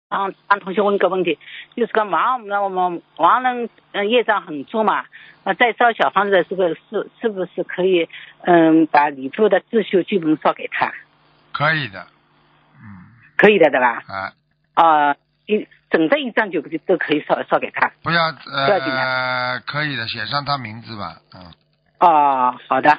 女听众：
台长答： 可以，要讲清楚（平时念自修时要讲清楚？）